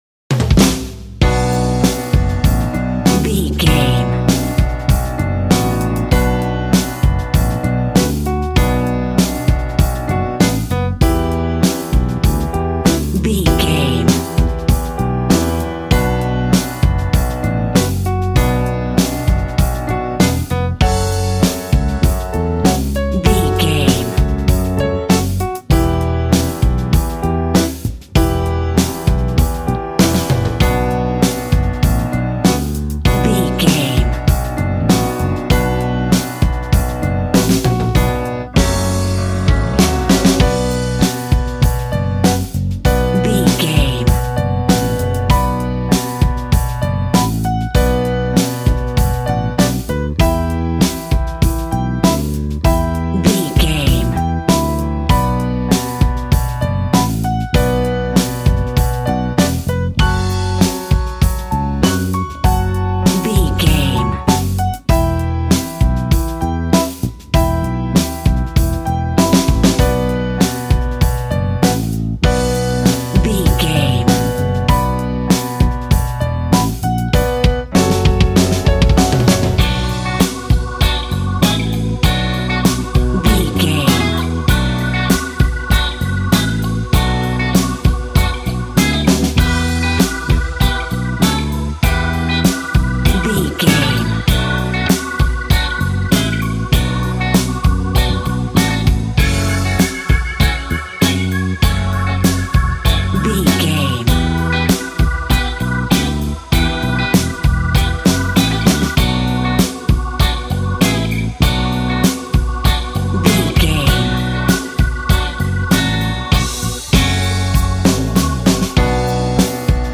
Aeolian/Minor
sad
mournful
bass guitar
electric guitar
electric organ
drums